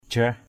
/ʥrah˨˩/